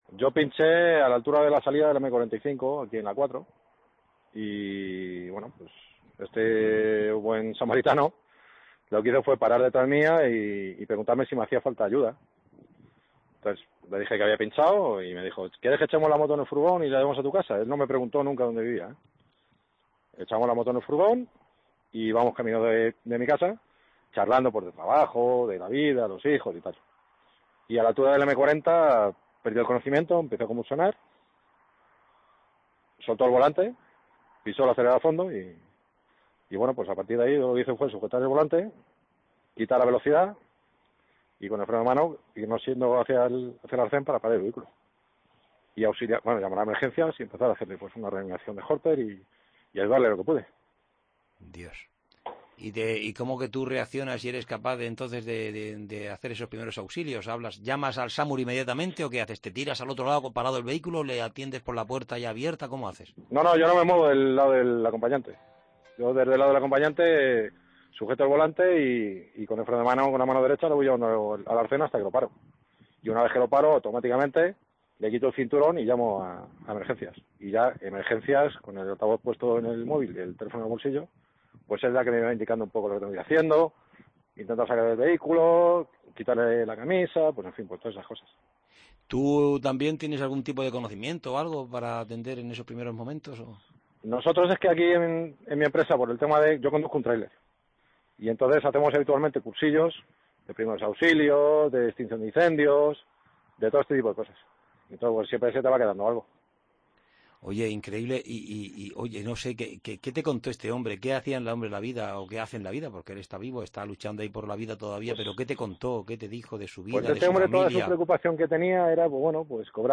Aquí puedes escuchar la conversación que mantuvieron minutos después del accidente: "Ni siquiera nos presentamos, tuve que sujetar con una mano el volante y con otra el freno".